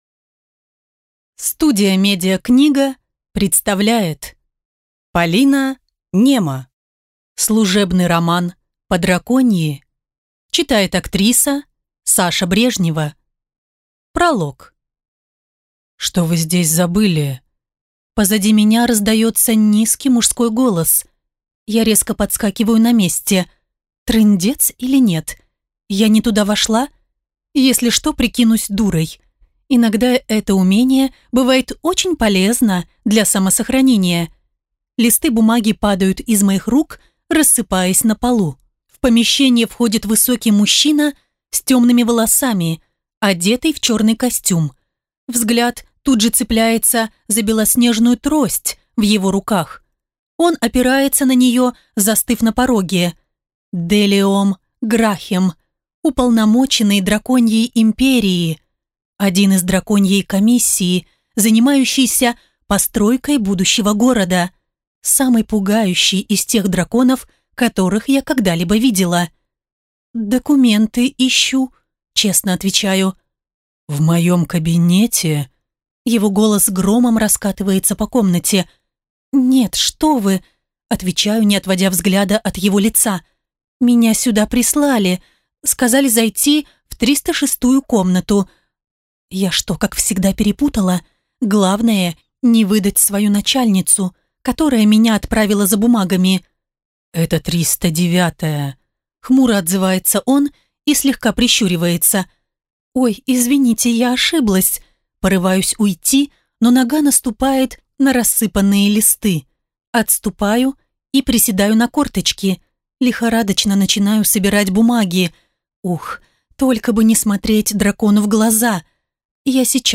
Аудиокнига Служебный роман по-драконьи | Библиотека аудиокниг